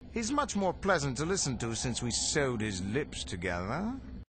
―Satal Keto — (audio)